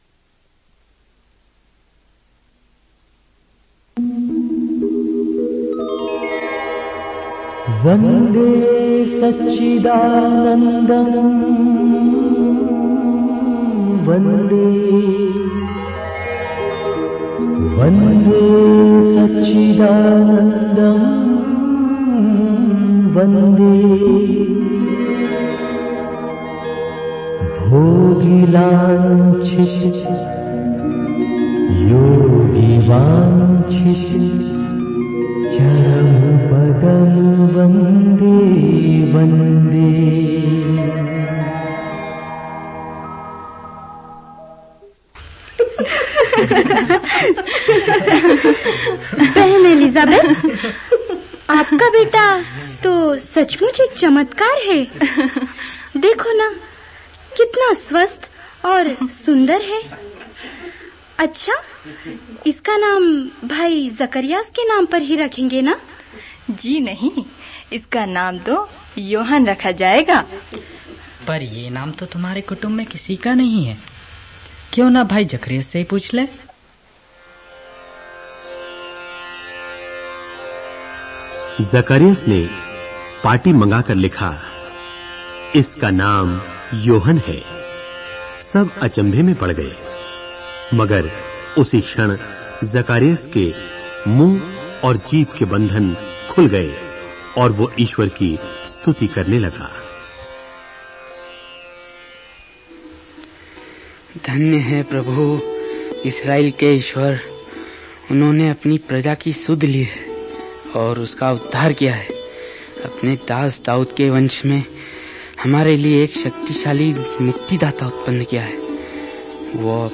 Bible Dramas